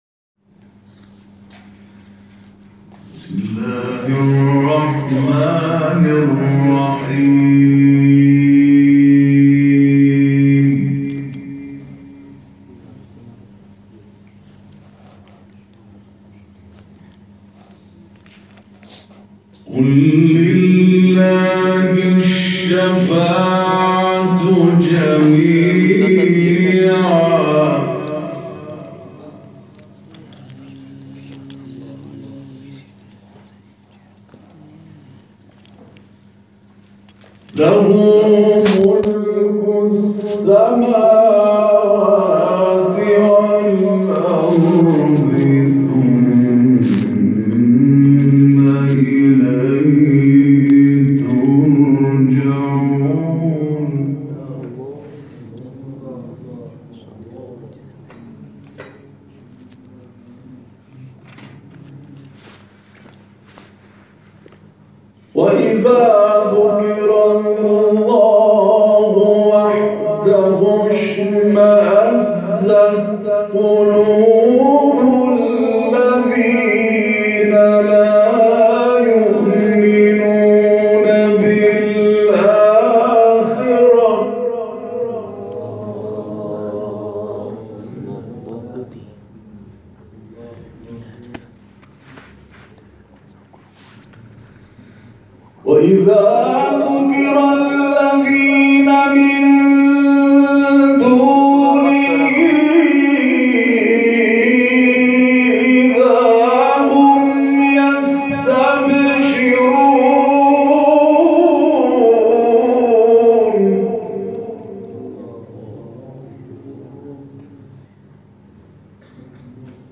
این تلاوت که به سبک عبدالعزیز حصان قرائت شده، در تاریخ 27 شهریور ماه در کارگاه آموزشی قرائت قرآن در مسجد قره باغی‌های تبریز اجرا شده است.